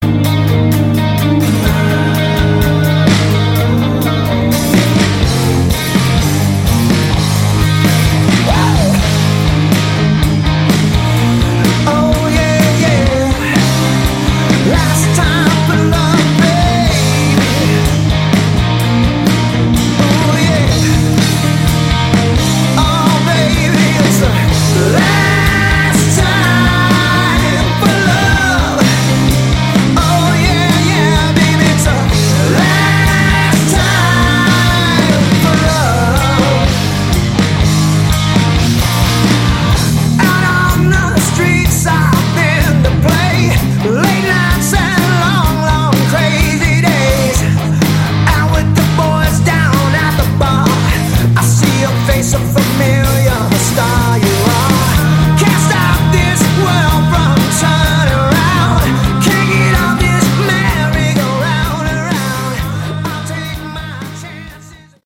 Category: Hard Rock
lead vocals, keyboards, acoustic guitar
drums, percussion
guitar, keyboards
bass, vocals